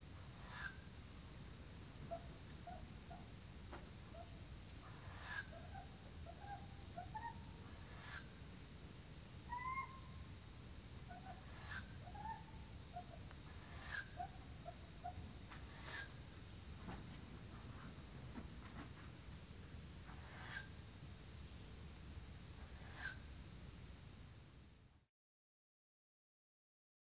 Her version was markedly higher-pitched than his (CD4-51).
4-51-Humes-Owl-Presumed-Feeding-Calls-Of-Female-With-Begging-Of-Nestling.wav